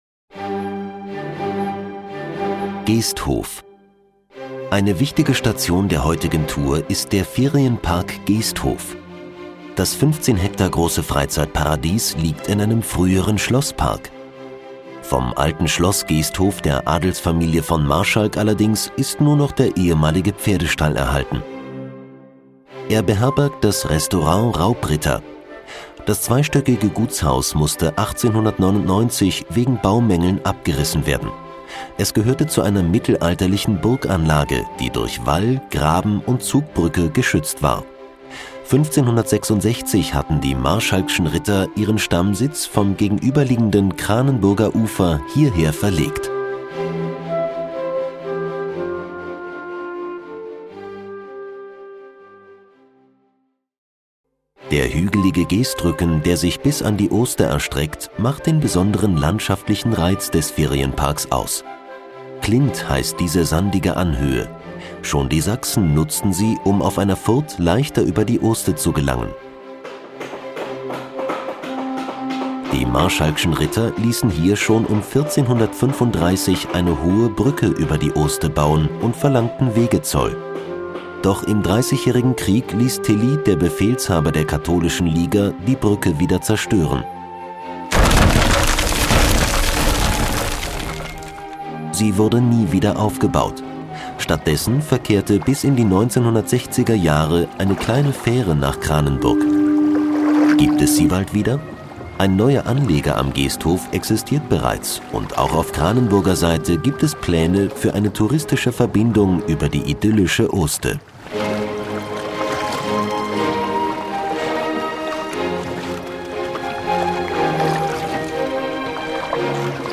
Geesthof - Kinder-Audio-Guide Oste-Natur-Navi